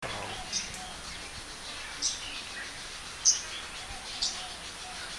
Calls
6 May 2012 Po Toi (1059)